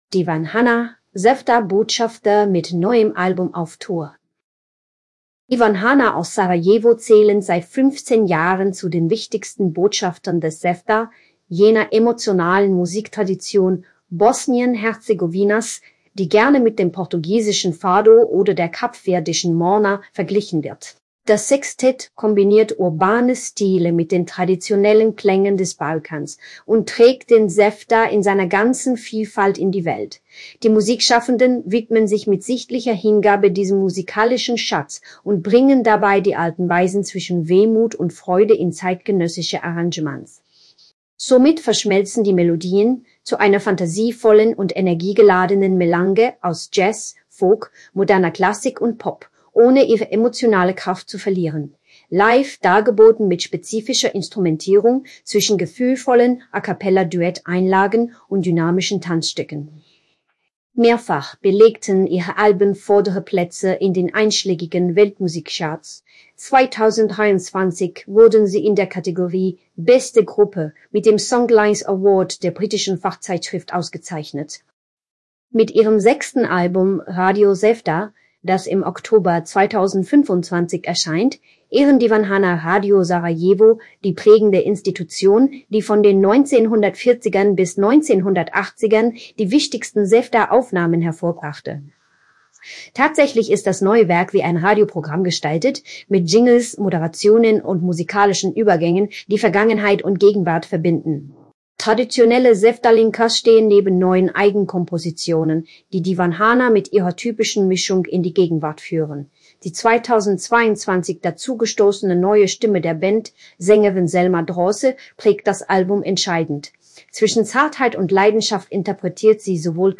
Gelesen von einem generierten Stimmklon eines unserer Redakteure. Diese Technologie ist noch sehr neu, bitte erwarten Sie keine Perfektion.